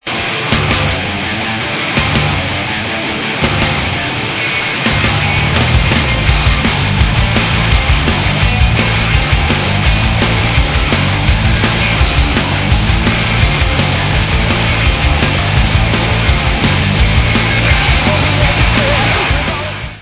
Live From Le Zenith